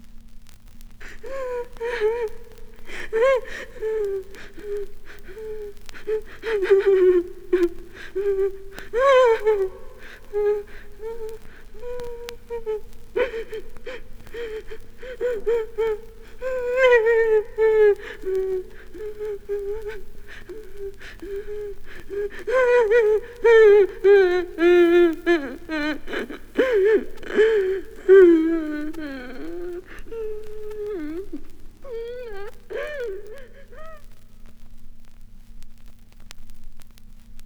• one woman sobbing.wav
one_woman_sobbing_1Rd.wav